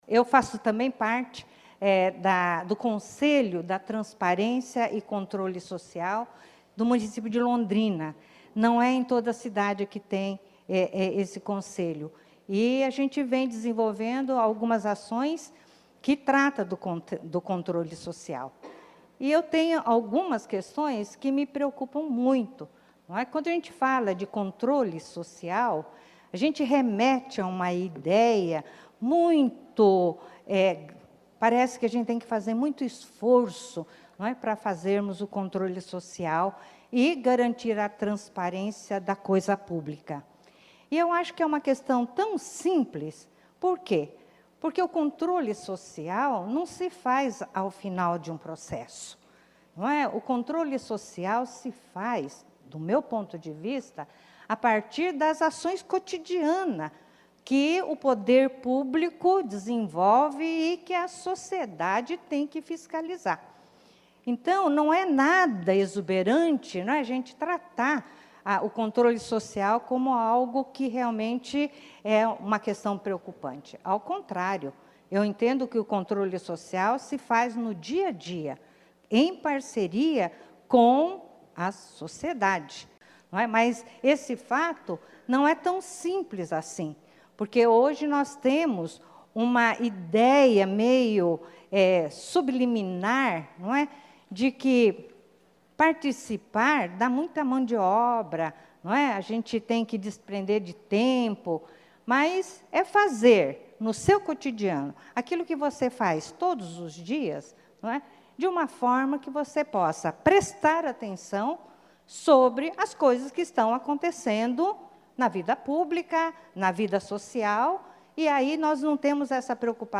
IV Sined e III Encontro de Promotores e Promotoras de Justiça da Educação - áudios dos participantes
Painel "Educação, controle social e relação com entidades do setor públicos"